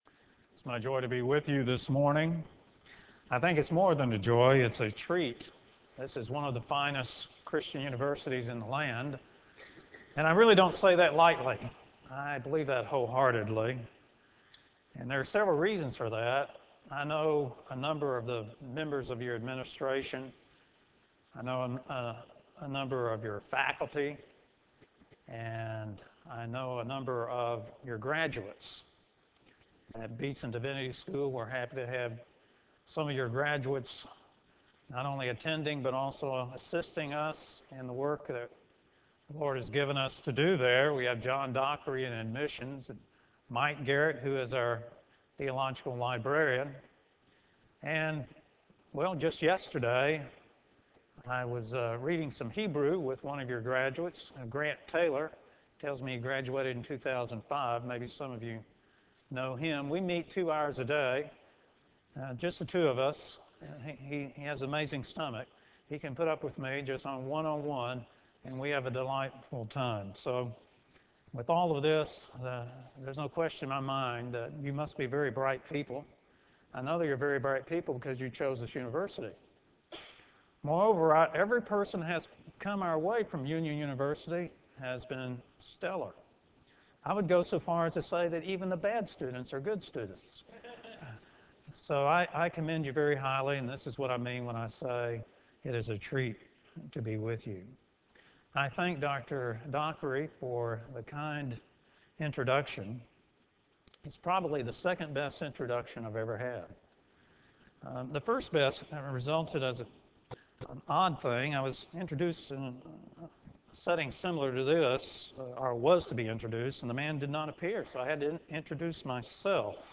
Ryan Center Conference Chapel